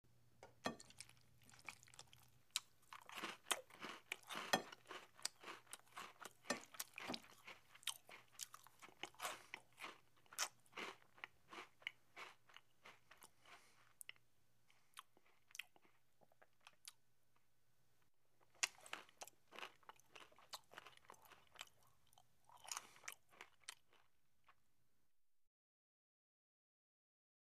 Chewing
Eating With Fork And Plate Scrape And Lip Smacks Some Crunchy Chewing